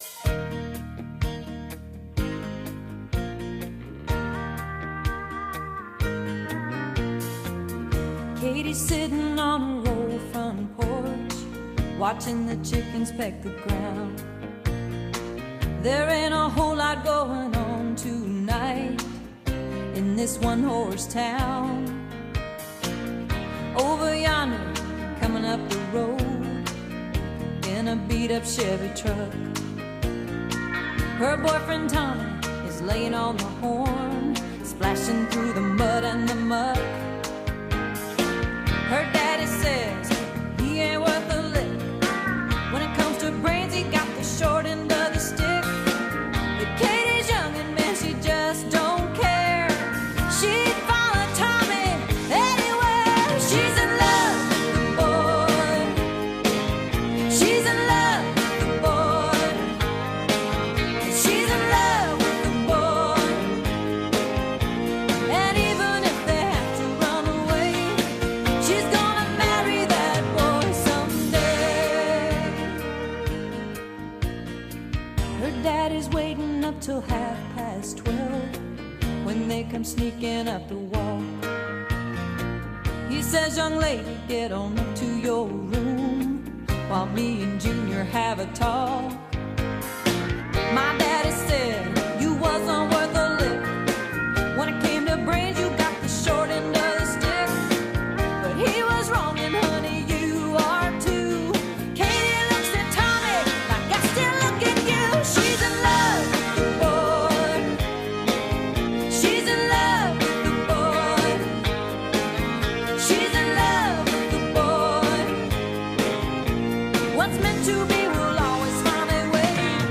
BPM125
Audio QualityMusic Cut
UPDATE (1/26/26): made audio louder